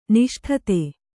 ♪ niṣṭhate